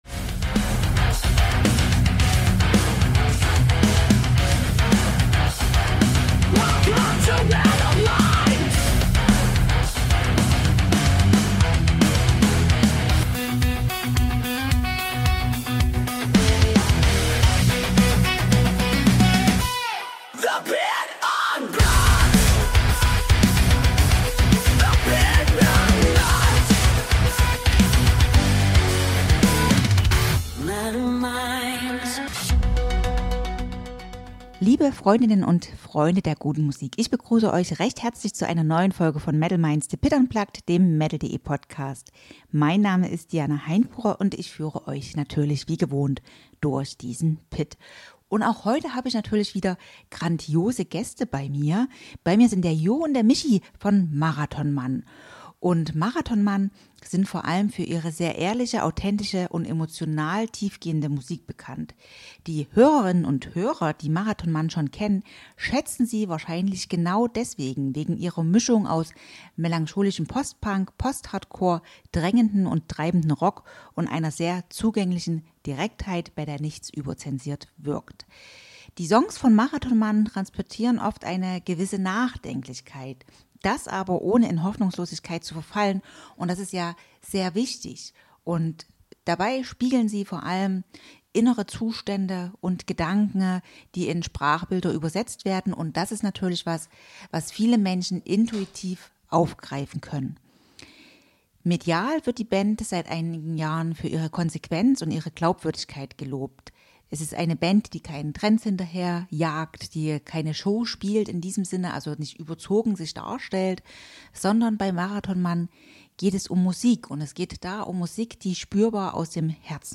Ein Gespräch über Echtheit, Teamgeist und das gute Gefühl, wenn Musik wieder atmet.